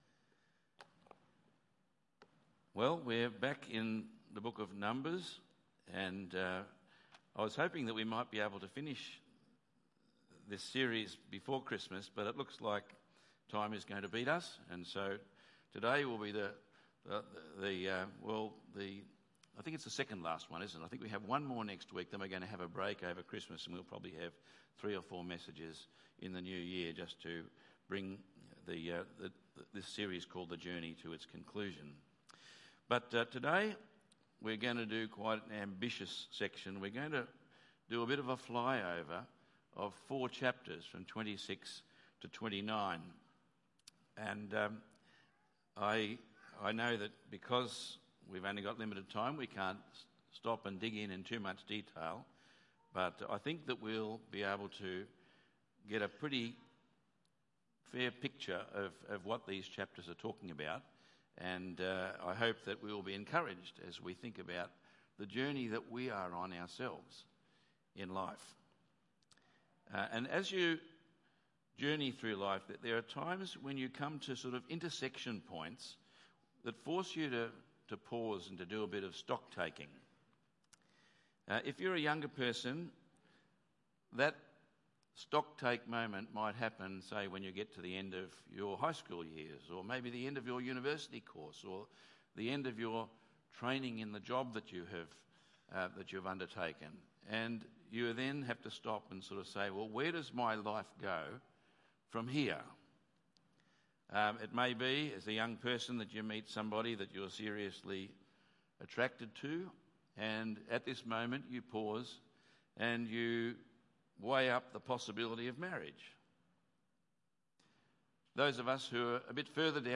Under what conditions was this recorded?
Tagged with Sunday Morning